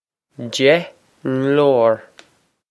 deh nglowr
This is an approximate phonetic pronunciation of the phrase.